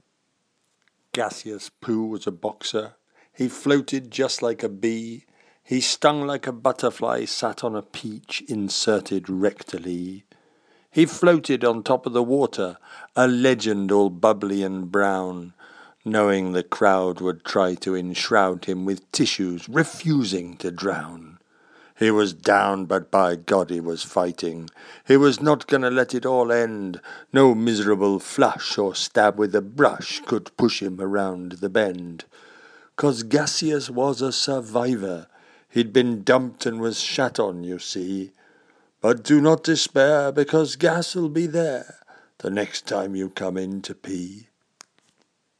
A poem about Gaseous Poo, the boxer.